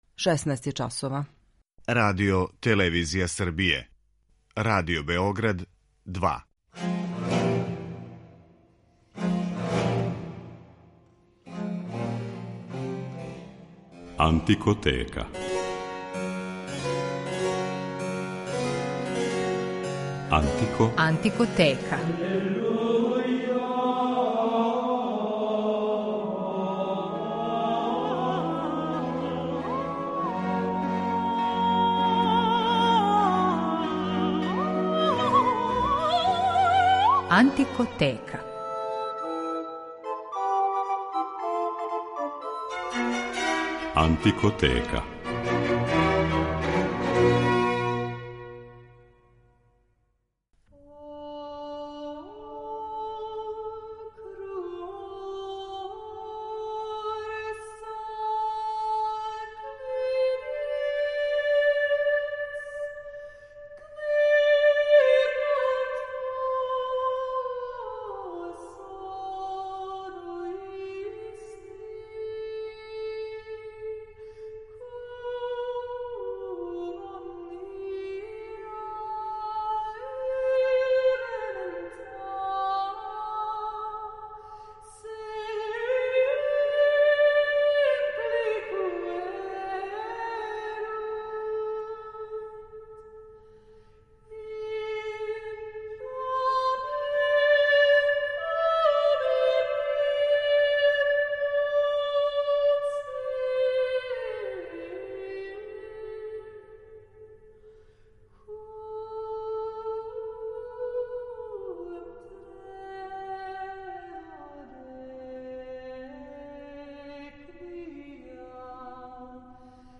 Била је мистик, песник, композитор, космолог, проповедник, писала је текстове и о медицини и природи. Хилдегард фон Бинген је посвећена данашња емисија, у којој ћете слушати одломке њеног мистерија Ordo Virtutum , антифоне, секвенце и лауде Светој Урсули.